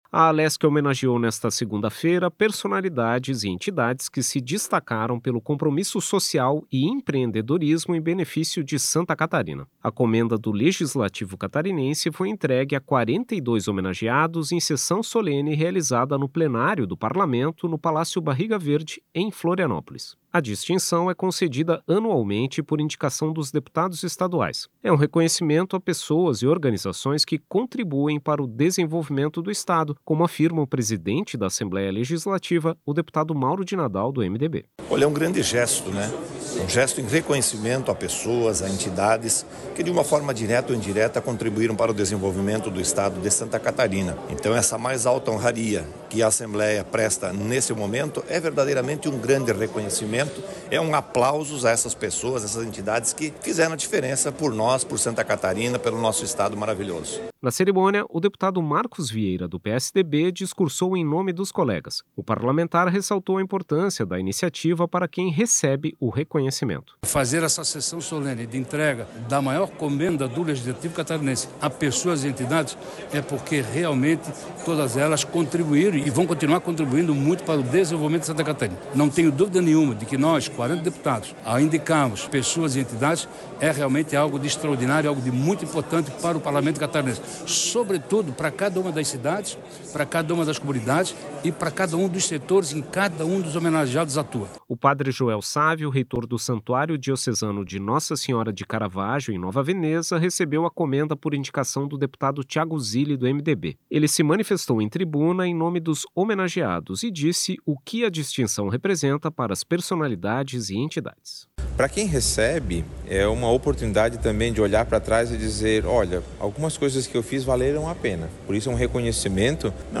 Entrevistas com:
- deputado Mauro de Nadal (MDB), presidente da Assembleia Legislativa;
- deputado Marcos Vieira (PSDB);